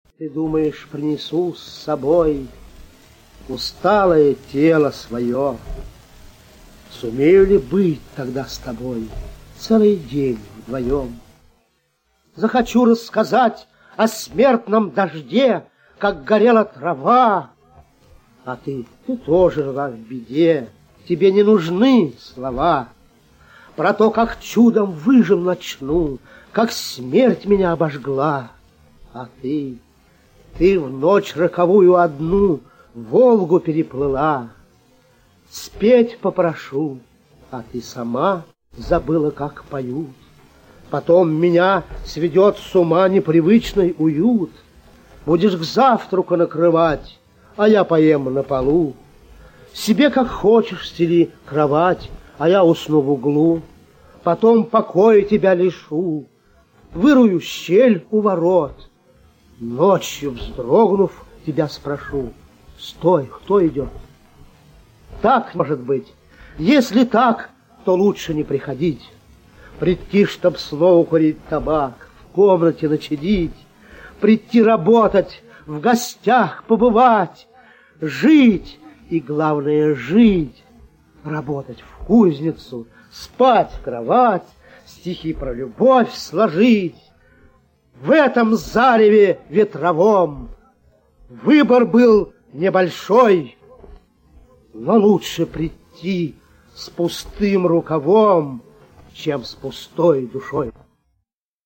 1. «Михаил Луконин – Приду к тебе (читает автор)» /
Lukonin-Pridu-k-tebe-chitaet-avtor-stih-club-ru.mp3